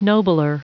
Prononciation du mot nobler en anglais (fichier audio)
Prononciation du mot : nobler